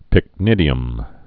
(pĭk-nĭdē-əm)